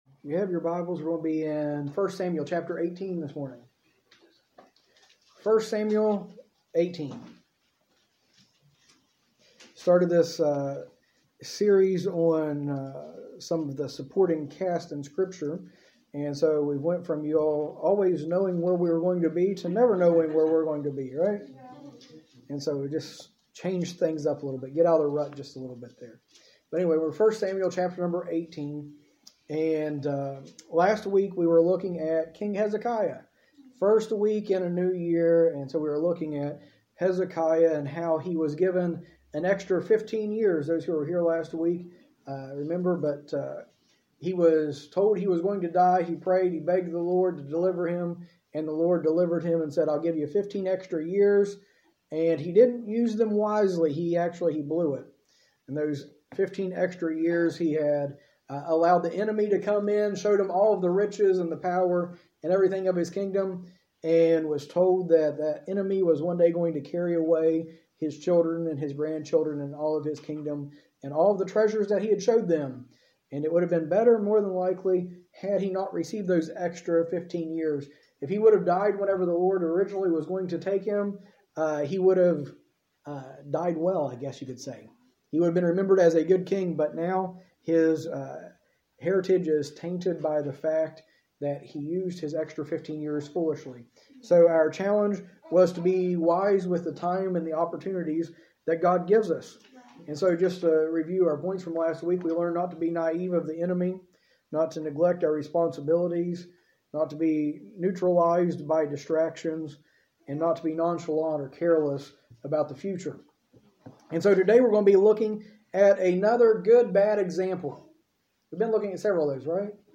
In this series, taught during our adult Sunday School, we explore basic principles and teachings of Christianity that will help us live our faith in everyday life.